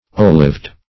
Olived \Ol"ived\